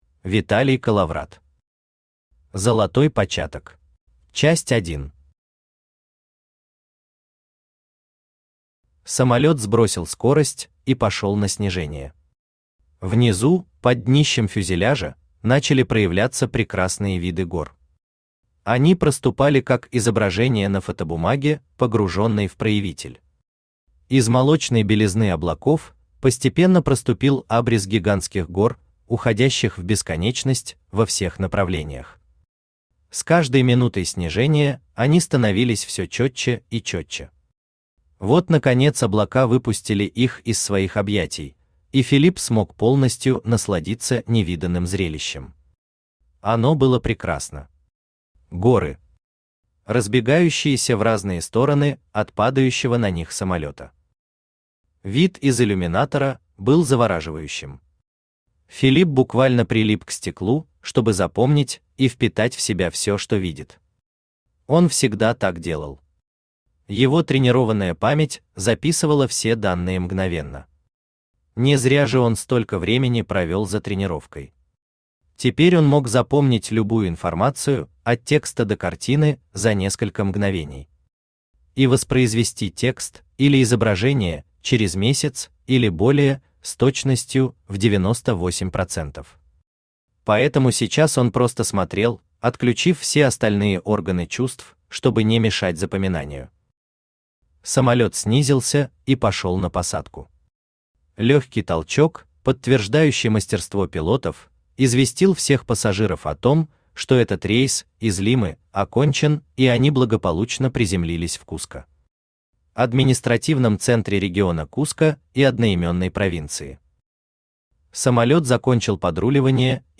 Аудиокнига Золотой початок | Библиотека аудиокниг
Aудиокнига Золотой початок Автор Виталий Колловрат Читает аудиокнигу Авточтец ЛитРес.